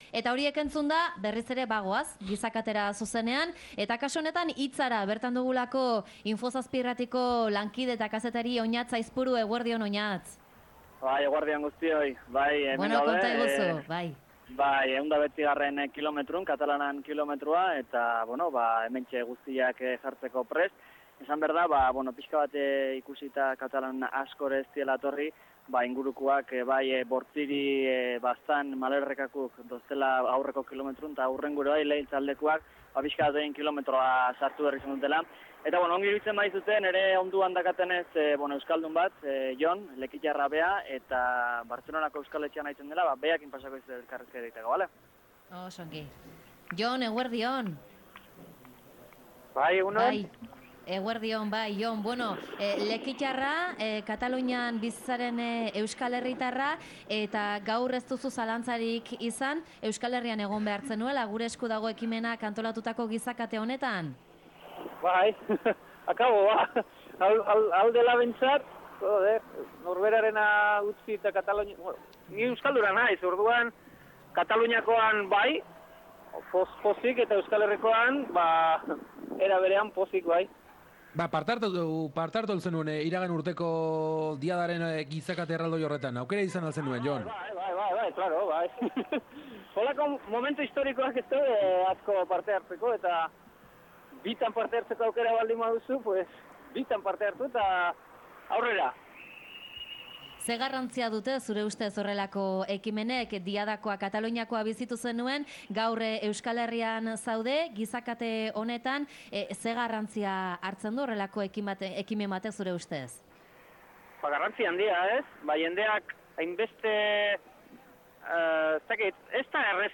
GURE ESKU DAGO: Katalanen Kilometrotik zuzenean